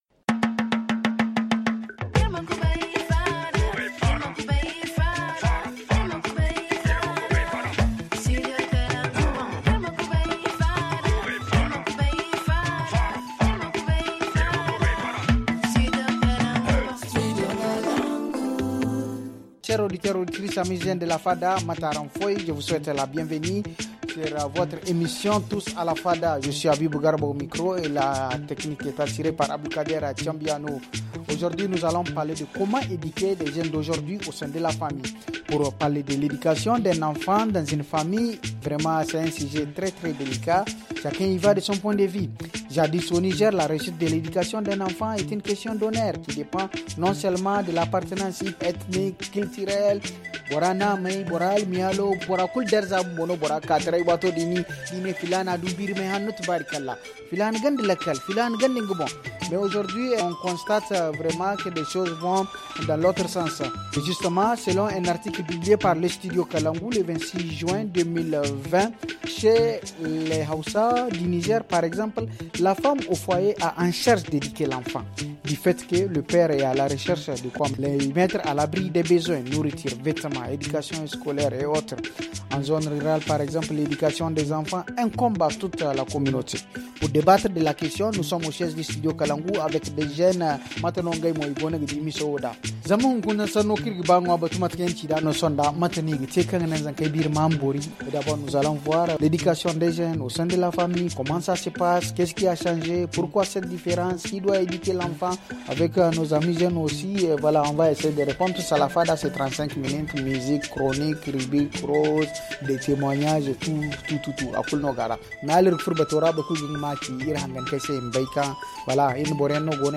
Pour débattre de la question nous sommes au siège du studio kalangou avec des jeunes et des moins jeunes.